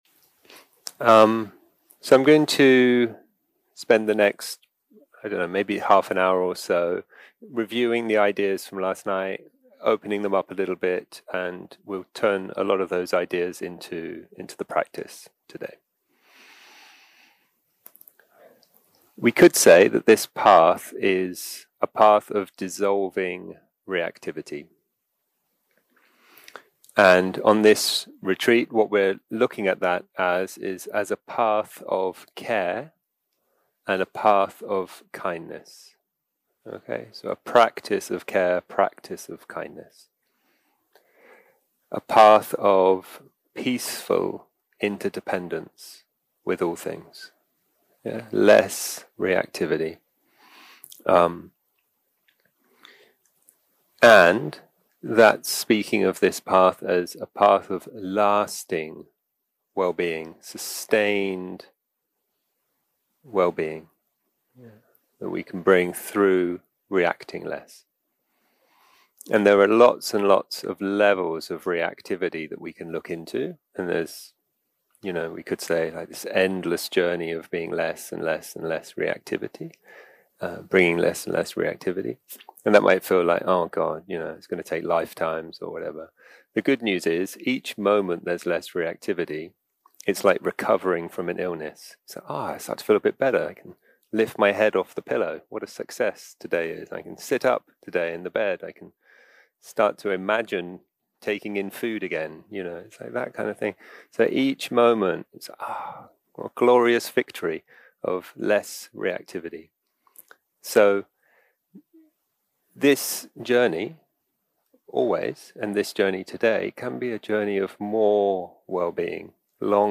יום 5 – הקלטה 11 – בוקר – הנחיות למדיטציה - Noticing More - Reacting Less 0:00 0:00 סוג ההקלטה: Dharma type: Guided meditation שפת ההקלטה: Dharma talk language: English